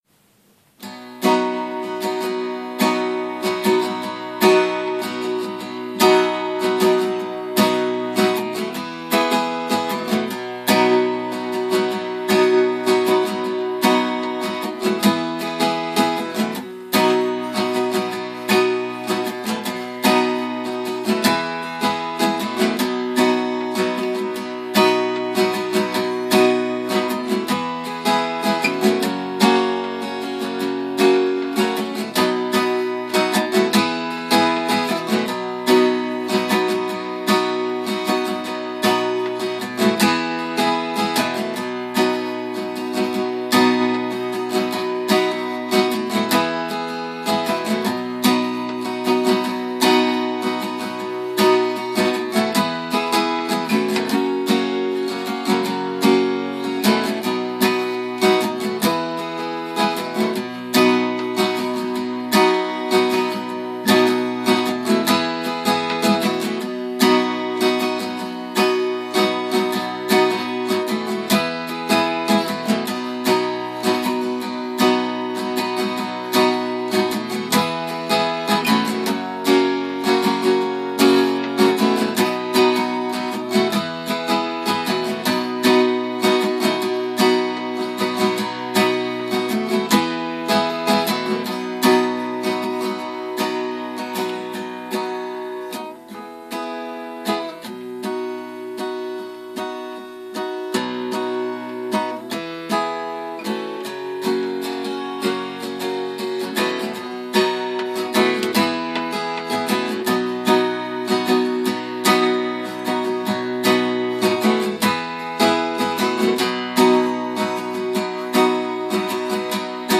Chansons en version instrumentale - Sac d'école : ressources pour la classe
L'instrumentalisation est faite à la guitare.
Version instru des radis de Monsieur Louis